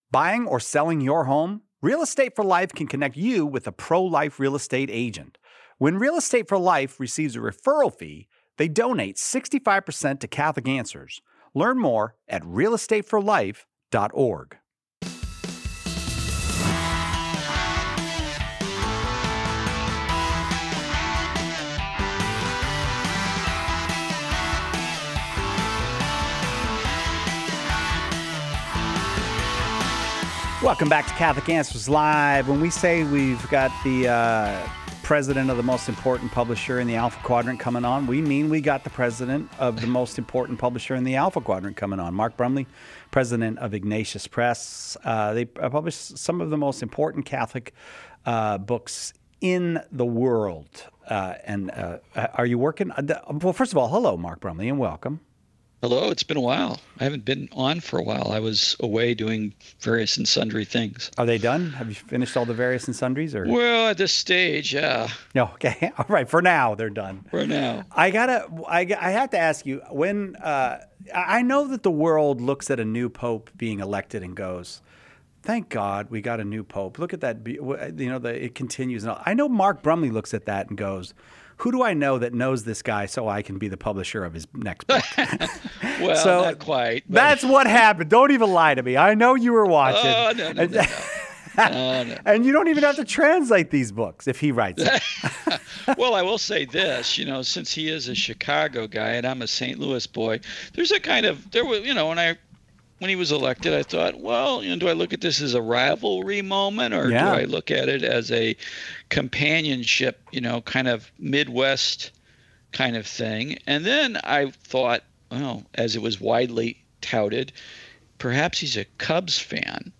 In this episode, Catholic apologists address a Protestant caller’s concern about Psalm 51 and the Catholic rejection of penal substitution. Learn how the Church understands Christ’s sacrifice—not as punishment in our place, but as a redemptive offering rooted in love and union with humanity.